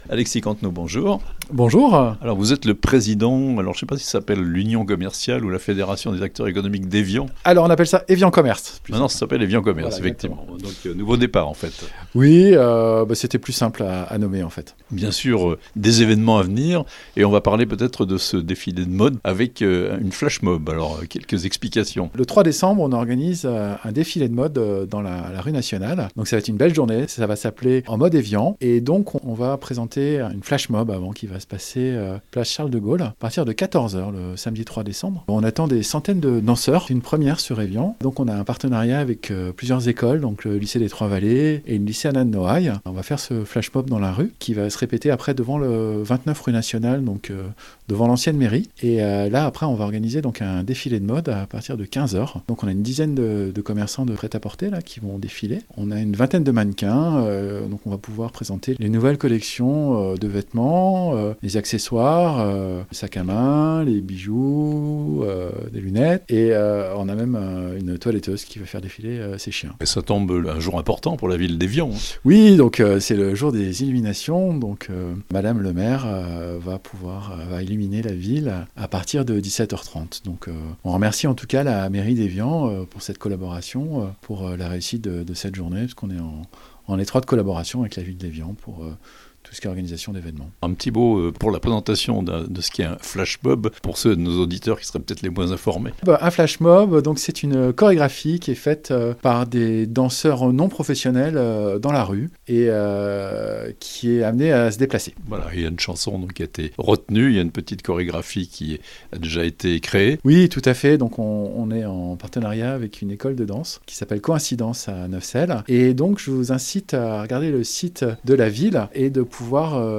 Les adhérents de l'association Évian Commerces organisent un défilé de mode le samedi 3 décembre (interview)